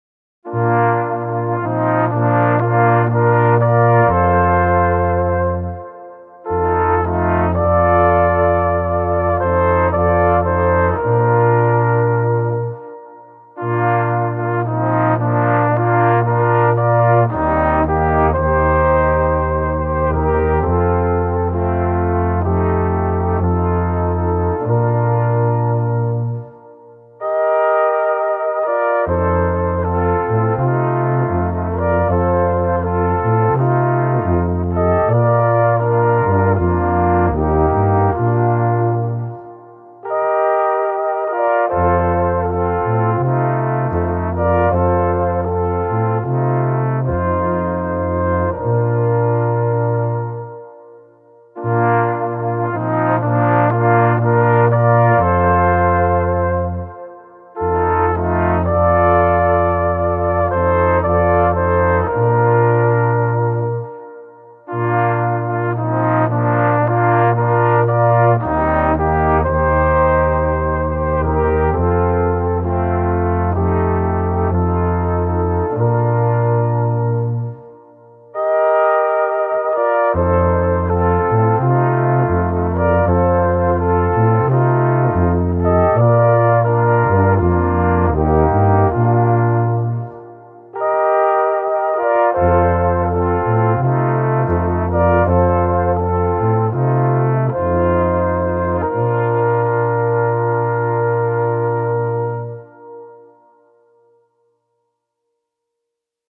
Musikalische Harmonie in vier Stimmen
aus Ramsau im Zillertal (Tuba)
aus Ried im Zillertal (Posaune)
(Flügelhorn)